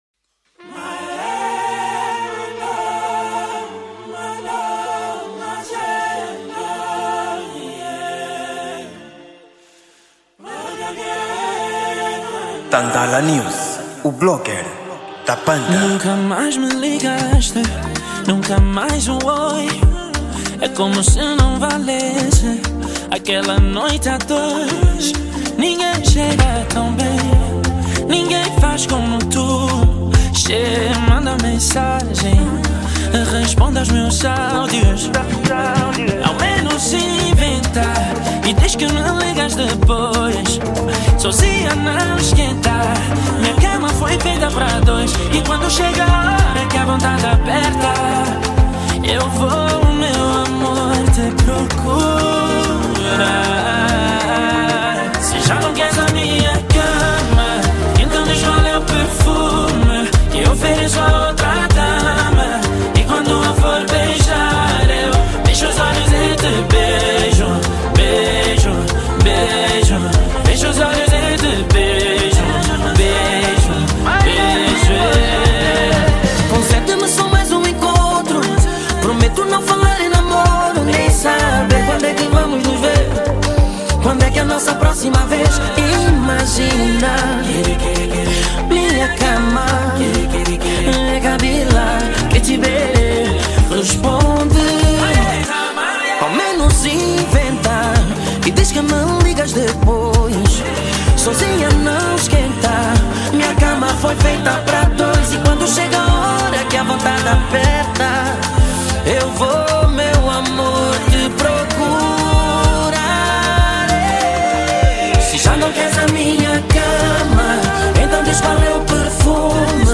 Género: Afro Pop